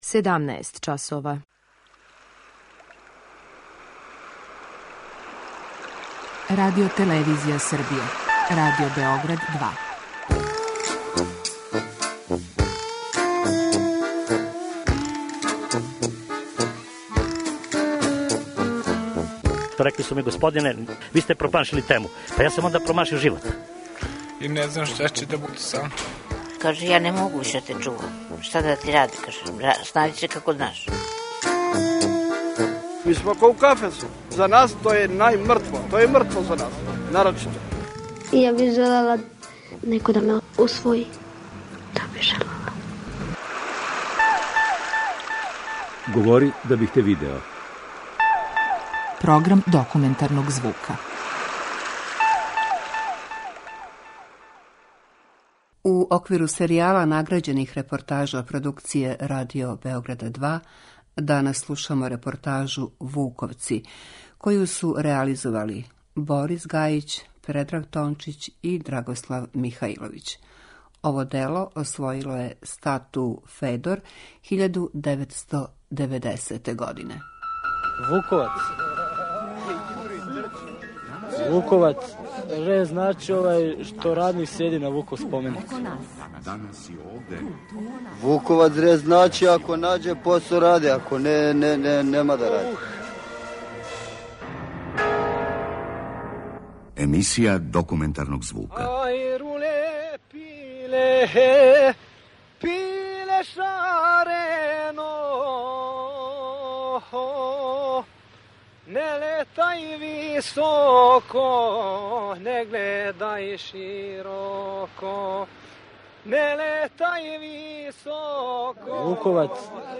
Документарни програм: Серијал награђених репортажа
Ова оригинална продукција Радио Београда 2 сједињује квалитете актуелног друштвеног ангажмана и култивисане радиофонске обраде.